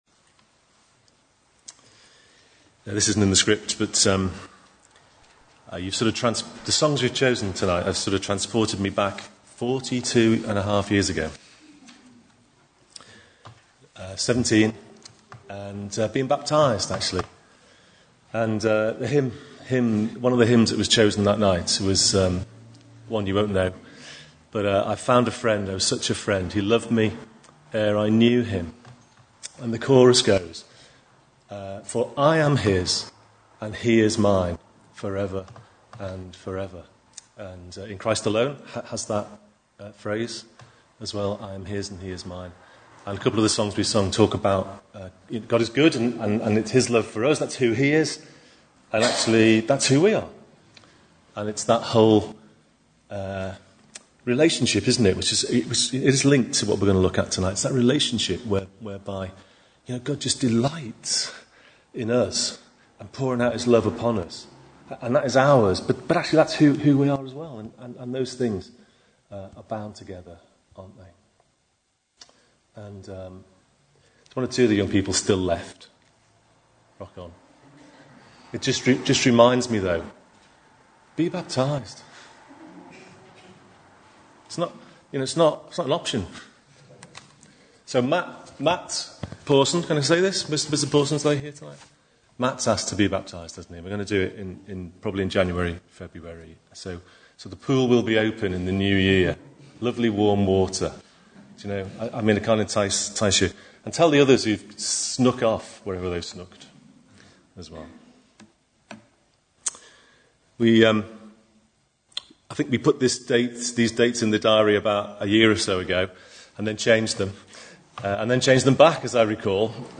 Talk 1 - Growing fruit
Church Weekend at Home 2016